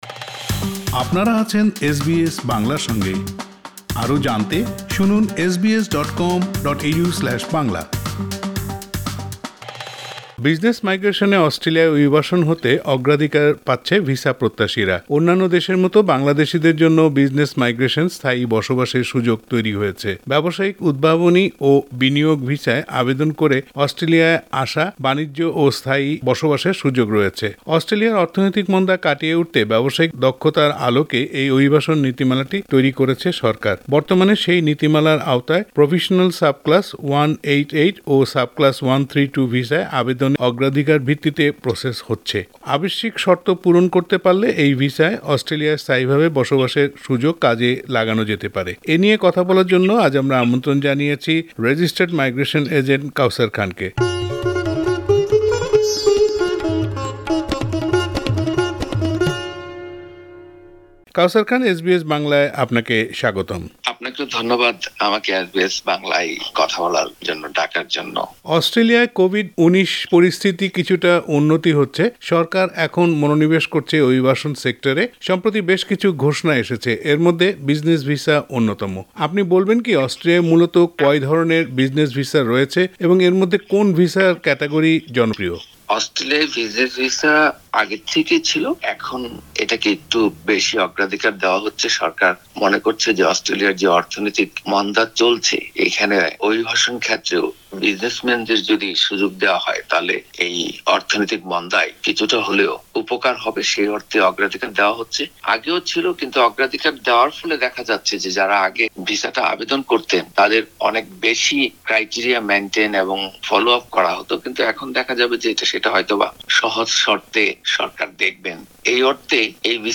এ নিয়ে এসবিএস বাংলার সাথে কথা বলেছেন রেজিস্টার্ড মাইগ্রেশন এজেন্ট